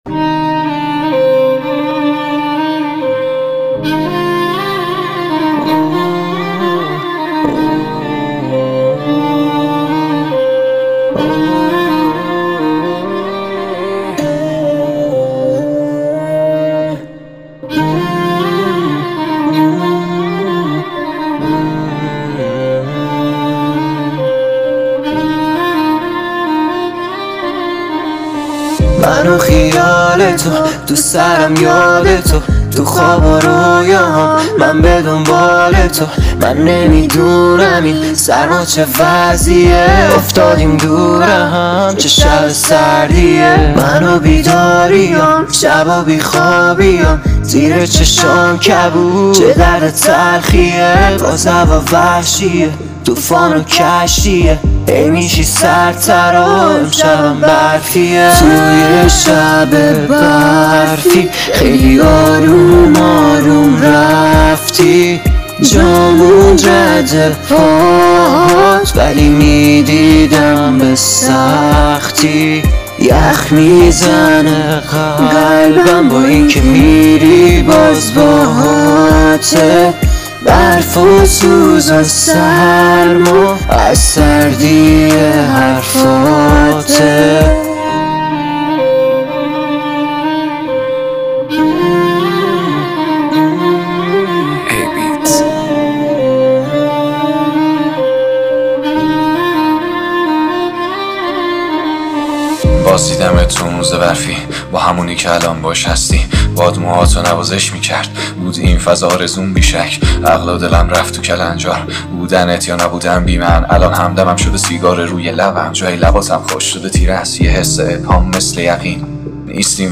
ویولن